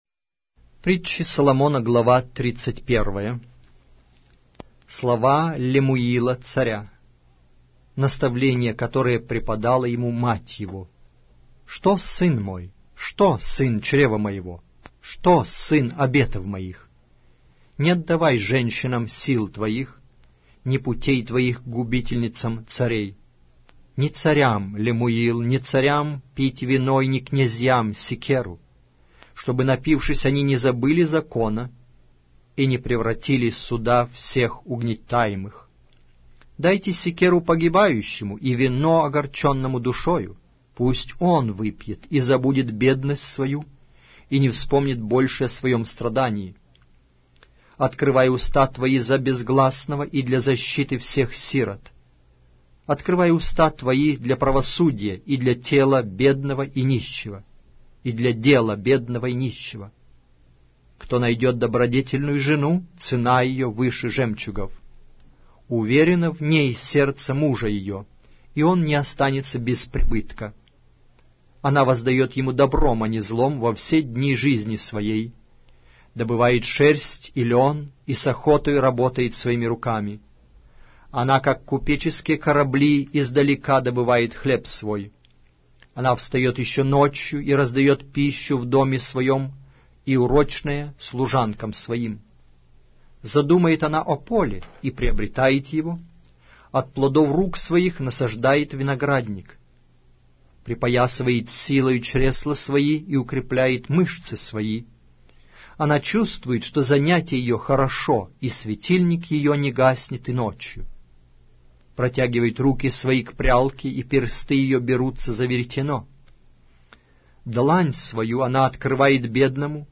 Аудиокнига: Притчи Соломона